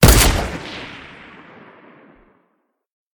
mgun1.ogg